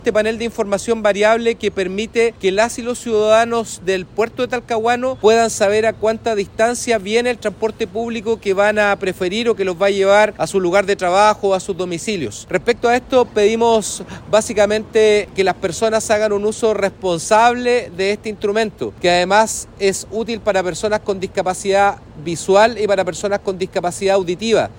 El proyecto busca que personas con discapacidad visual y auditiva puedan ser beneficiadas con la instalación, así lo dijo el seremi de transportes y telecomunicaciones de la región del Biobío, Patricio Fierro, quien detalló que ahora los paraderos contarán con sistema braille.